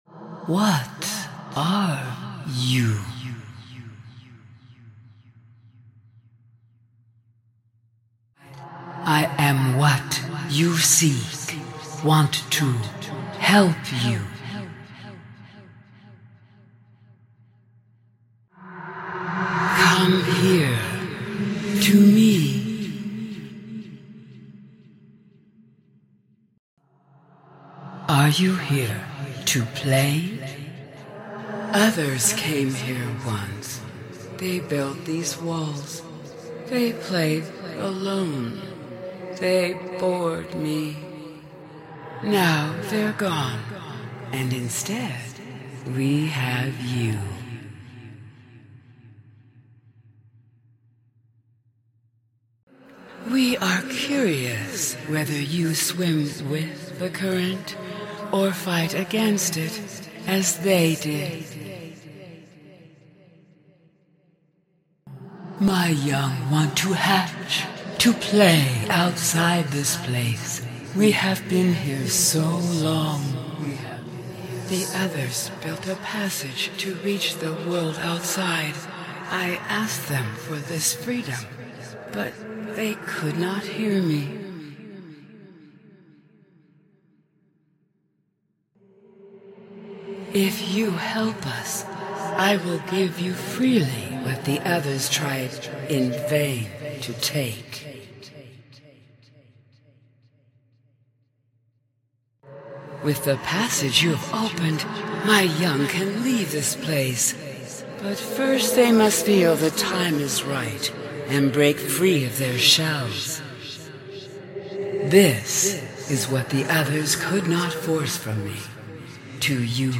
Sea Emperor Leviathan Voice and sound effects free download
Sea Emperor Leviathan Voice and Sounds! Including babies!